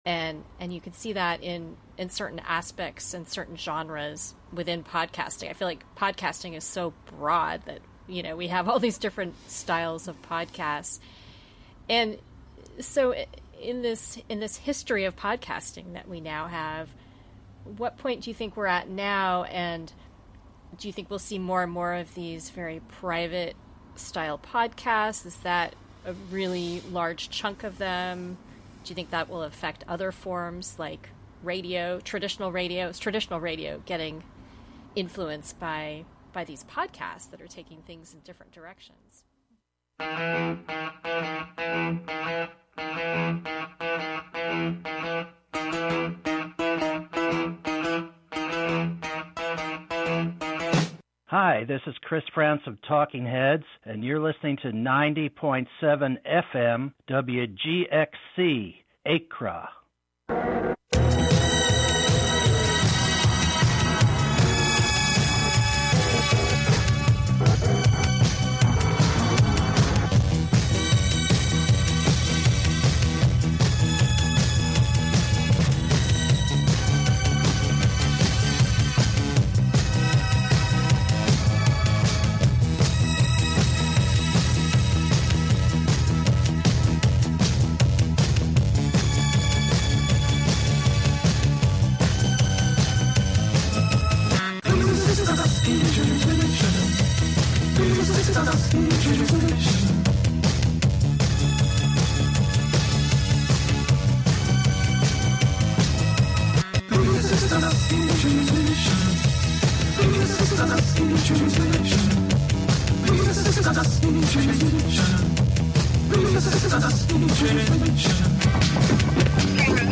Contributions from many WGXC programmers.
S1 EP6 - Coda - Beyond the Western Door: Magnetic on Main Every season, we will probe deeper into the mysteries of the storyline through a mystical sound and text experiment, plunging us beyond language, beyond narrative, Beyond the Western Door.
The "WGXC Morning Show" is a radio magazine show featuring local news, interviews with community leaders and personalities, reports on cultural issues, a rundown of public meetings and local and regional events, with weather updates, and more about and for the community, made mostly through volunteers in the community through WGXC.